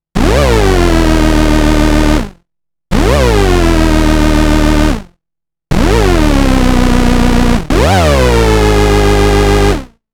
I also made hoover on the BS2 using the ring mod between saw and pulse with pwm to create saw pwm. Sounds pretty nice, but very different to your hoover :slight_smile: